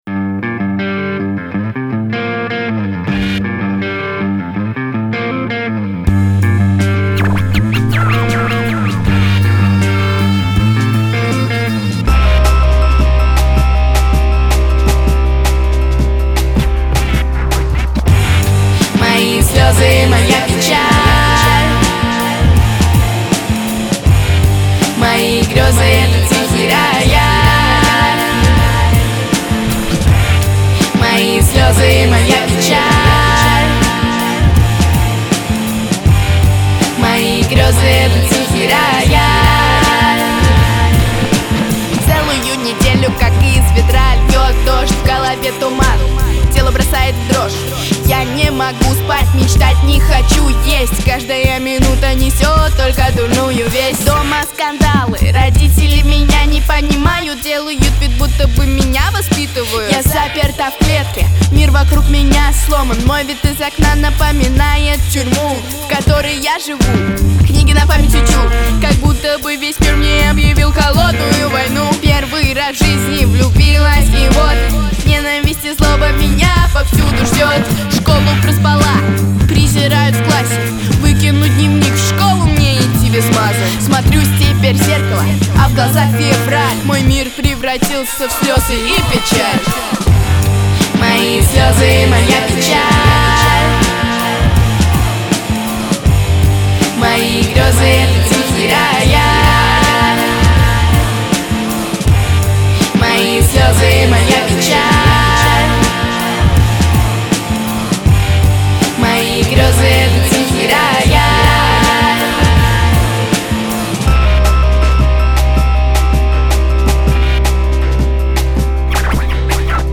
Грустные
Трек размещён в разделе Русские песни.